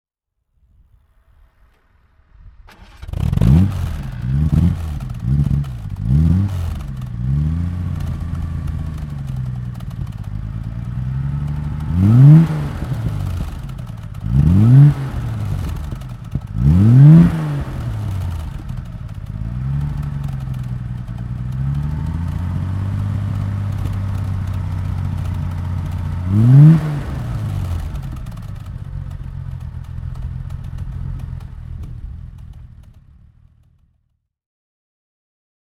Motorsounds und Tonaufnahmen zu Aston Martin Fahrzeugen (zufällige Auswahl)
Aston Martin DB6 Vantage Volante (1969) - Starten und Leerlauf
Aston_Martin_DB6_Volante_1969.mp3